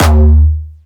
Jumpstyle Kick Solo
11 D#2.wav